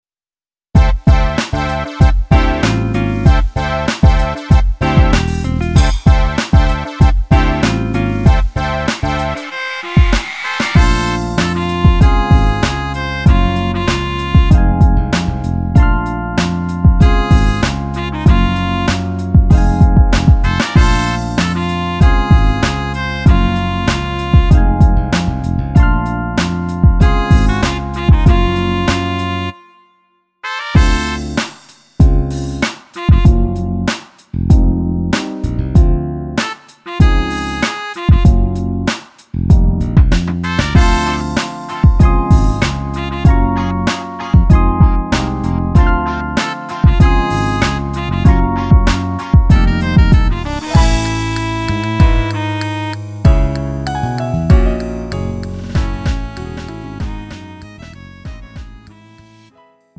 미리듣기
음정 원키 3:25
장르 가요 구분